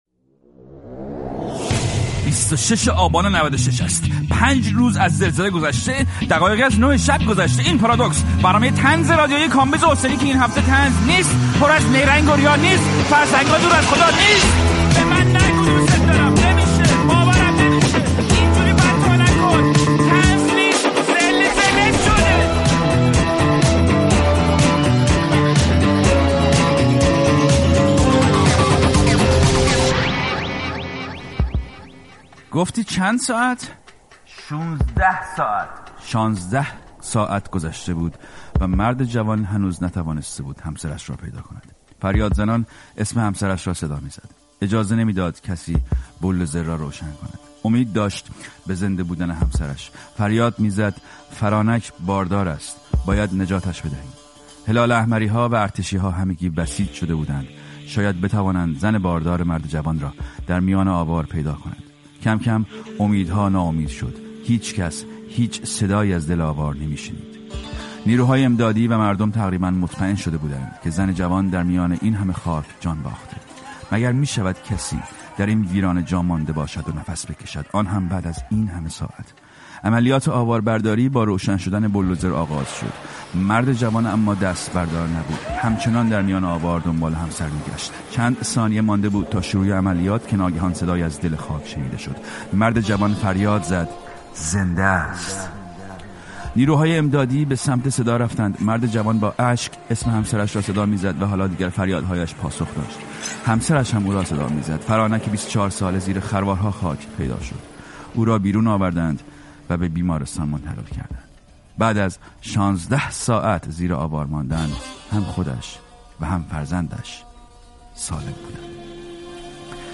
پارادوکس با کامبیز حسینی؛ گفت‌وگو با توکا نیستانی